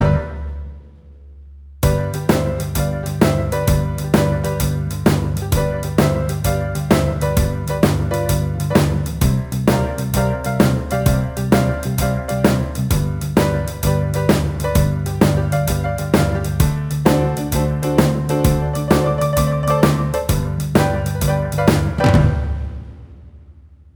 Minus Guitars Rock 'n' Roll 2:45 Buy £1.50